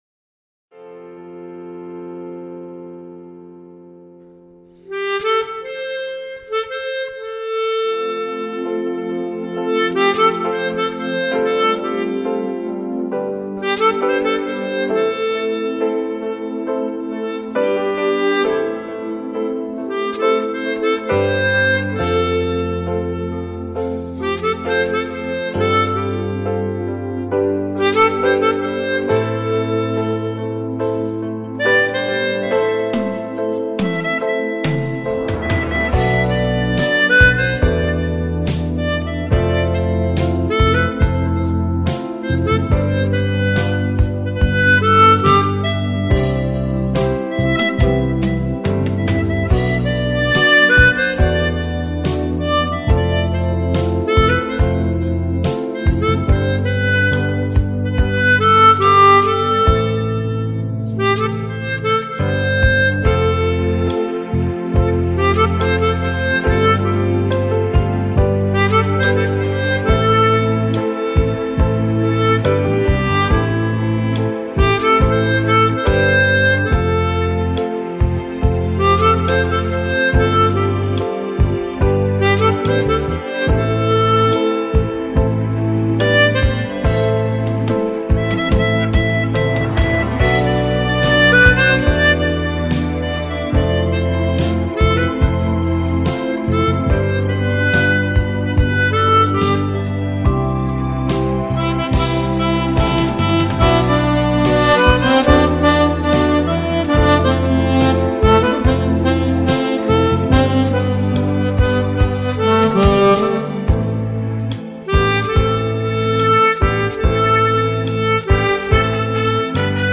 j'adore tes passages à l'octave